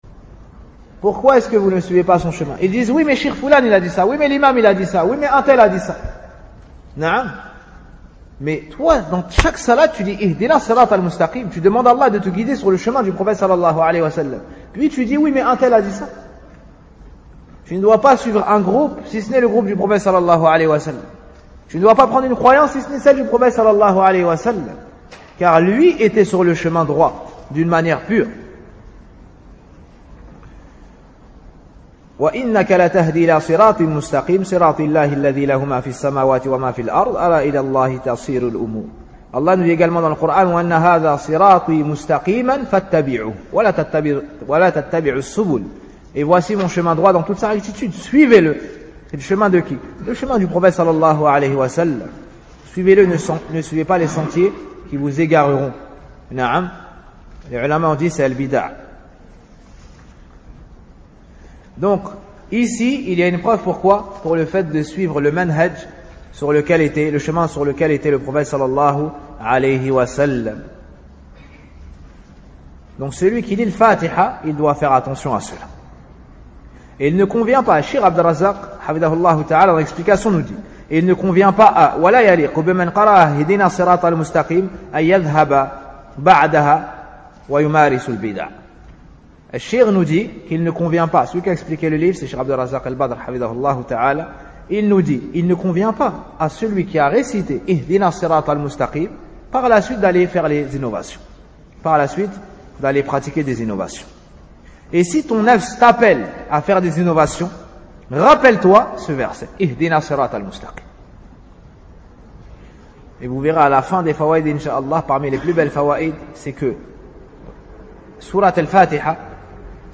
Cours n°3 : Suite Les Fawa'id à Tirer de Sourate Al Fatiha